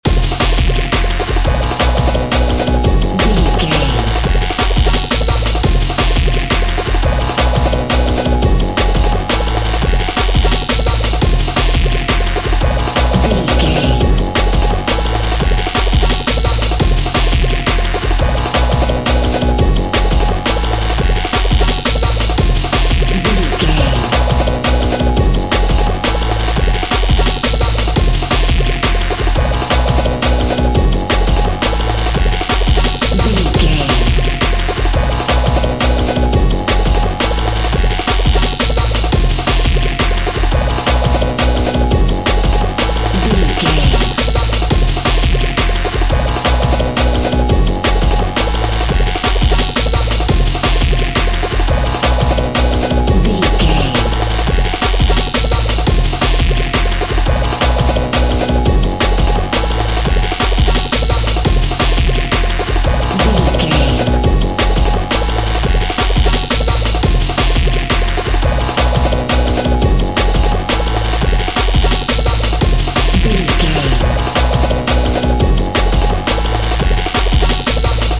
Aeolian/Minor
F#
Fast
futuristic
hypnotic
industrial
frantic
aggressive
dark
drum machine
synthesiser
electronic
synth bass
synth lead
synth pad
robotic